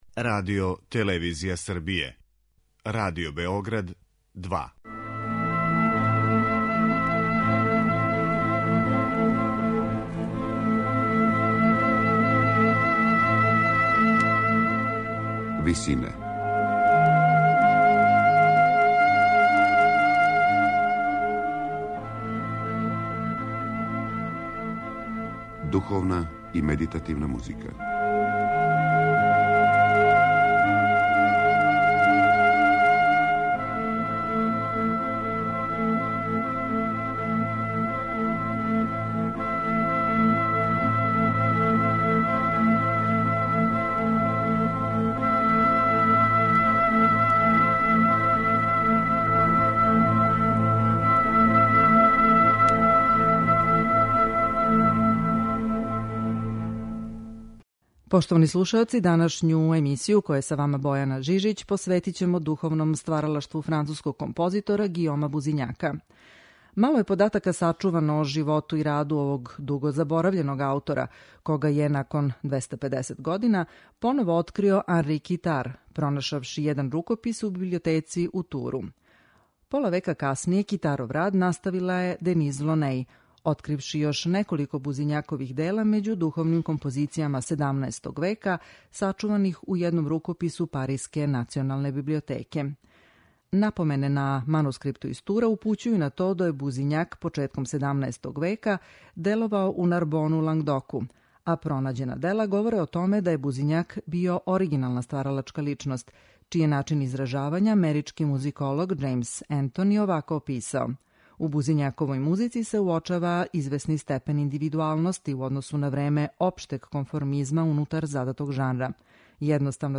Емисија духовне музике
На крају програма, у ВИСИНАМА представљамо медитативне и духовне композиције аутора свих конфесија и епоха.